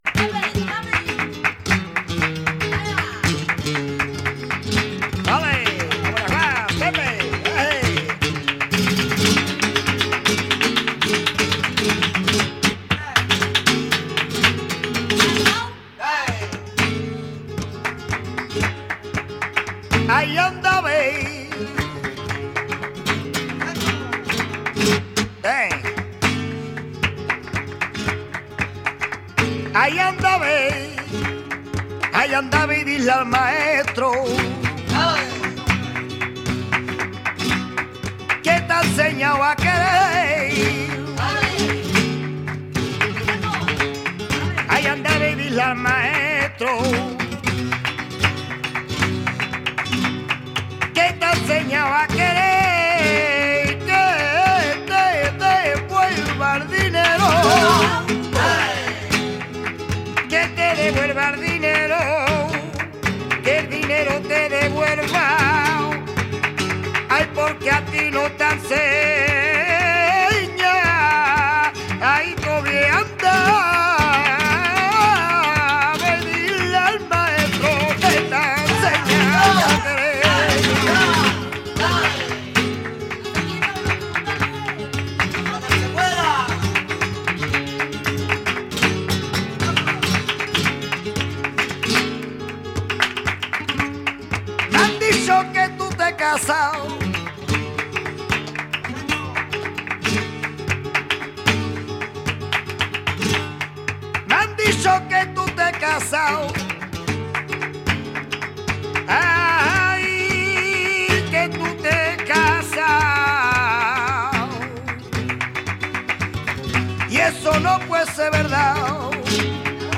Bulerías de création récente